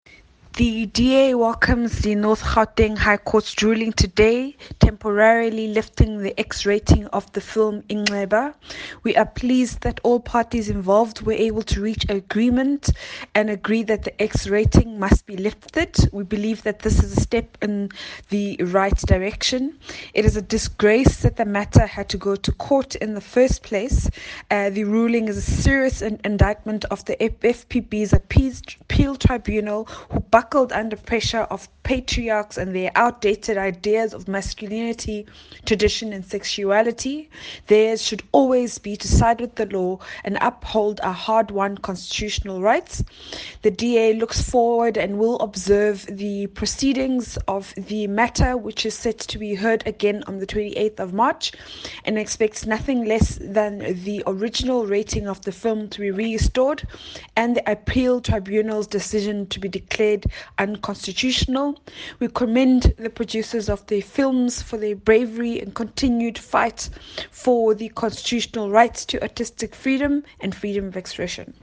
soundbite by the DA Shadow Minister of Communications, Phumzile Van Damme MP.